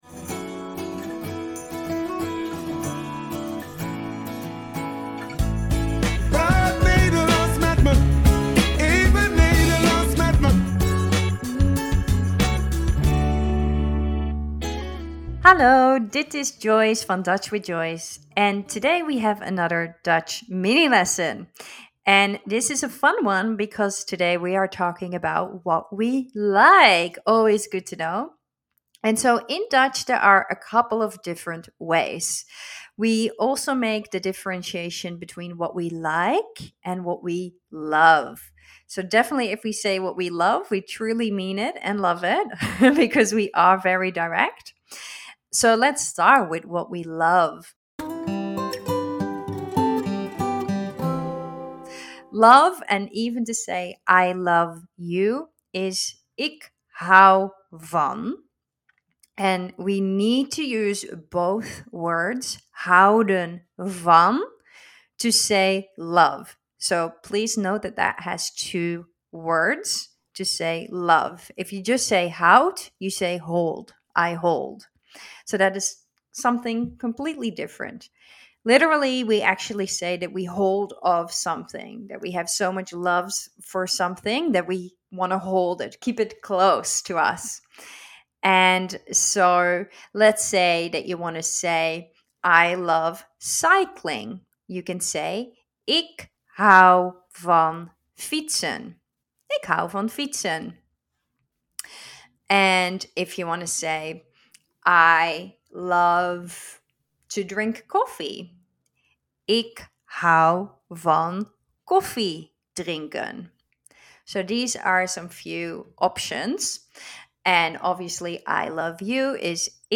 In this podcast series you will learn a few Dutch words and pronunciations every week in a few minutes.